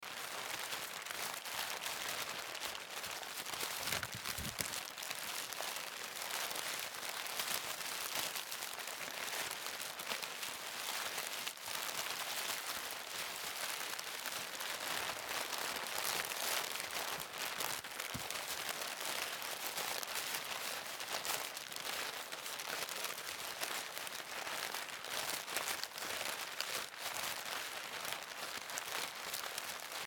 Звуки шуршания бумагой
Шелест упаковочной бумаги